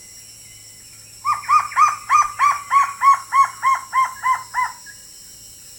Diard's Trogon
Rainforest Discovery Centre, Sepilok, Sandakan, Sabah, Malaysia
Harpactes diardii diardii
Trogon Diard's (diardii) SEPILOK SBH MYS song [A] ETSJ_LS_71748 (edit).mp3